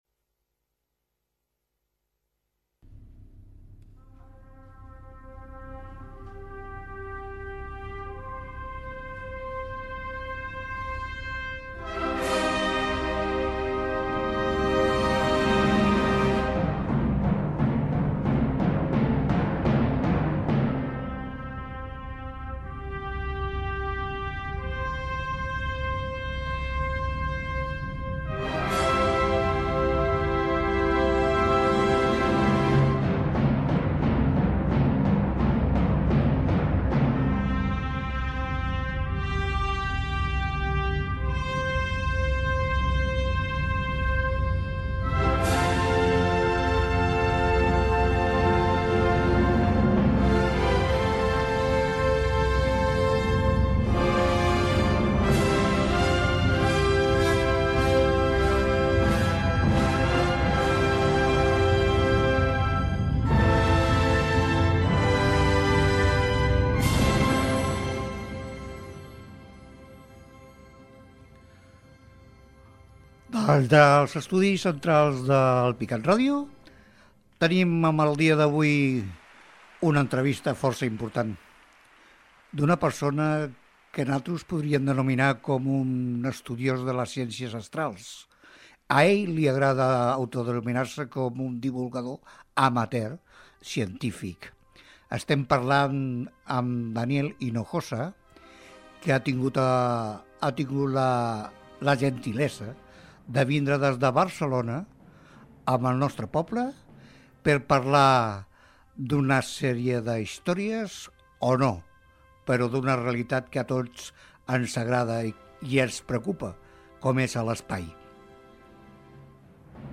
Identificació de l'emissora. Entrevista
Entreteniment